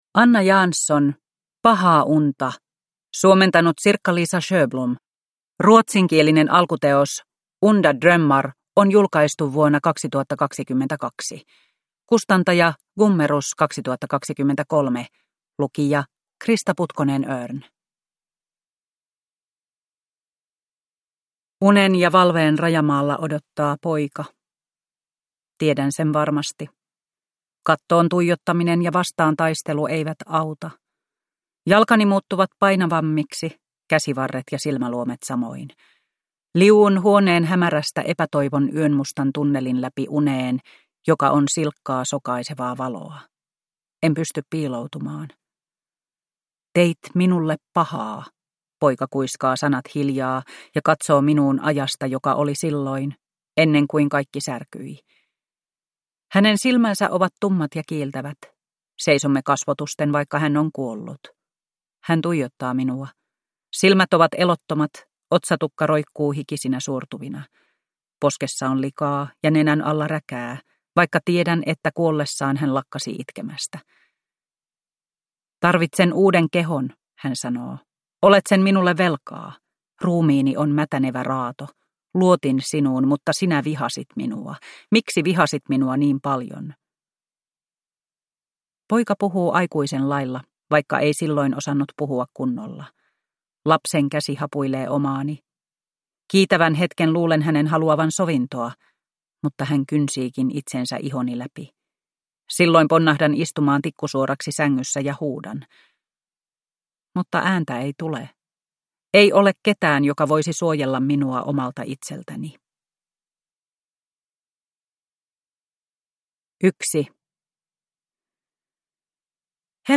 Pahaa unta – Ljudbok – Laddas ner